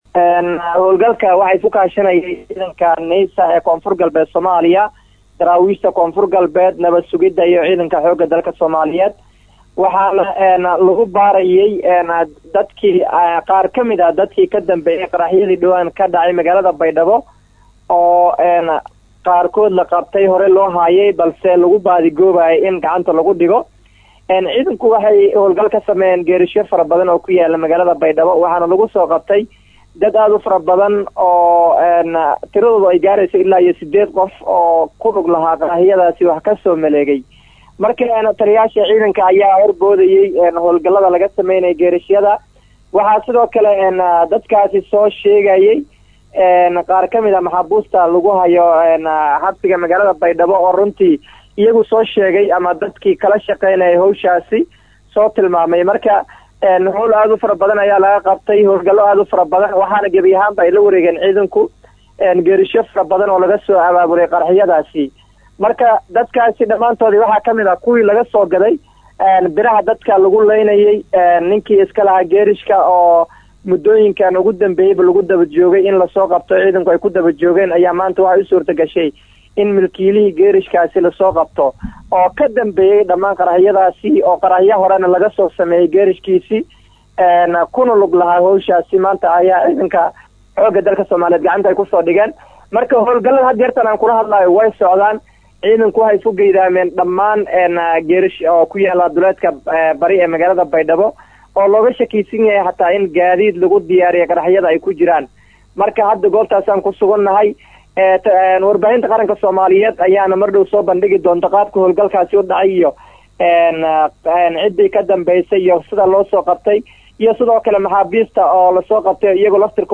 Halkan Ka Dhageyso Codka Wariye